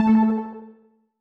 Techmino/media/effect/chiptune/ren_6.ogg at beff0c9d991e89c7ce3d02b5f99a879a052d4d3e